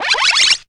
POWER UP ZAP.wav